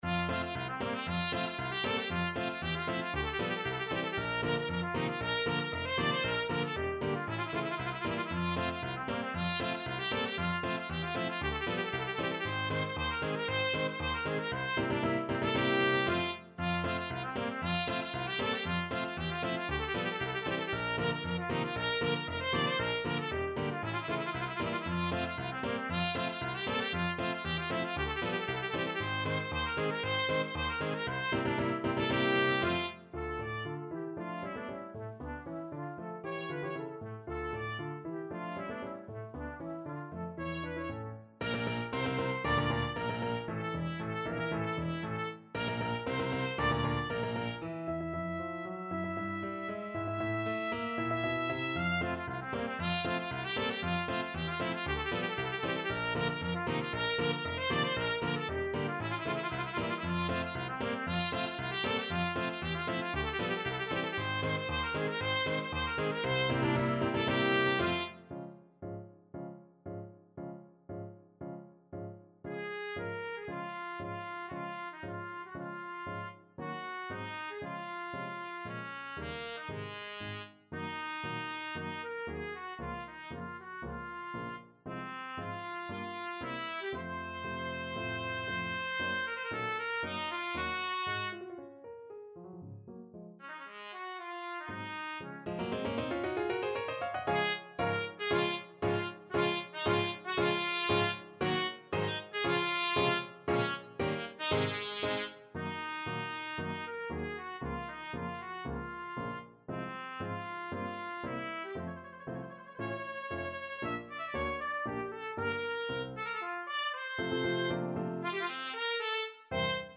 Classical Bizet, Georges Carmen Overture (Prelude) Trumpet version
Play (or use space bar on your keyboard) Pause Music Playalong - Piano Accompaniment Playalong Band Accompaniment not yet available transpose reset tempo print settings full screen
Trumpet
2/4 (View more 2/4 Music)
Ab4-F6
F major (Sounding Pitch) G major (Trumpet in Bb) (View more F major Music for Trumpet )
Allegro giocoso =116 (View more music marked Allegro giocoso)
Classical (View more Classical Trumpet Music)
carmen_overture_TPT.mp3